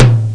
Gravis Ultrasound Patch
fftom6.mp3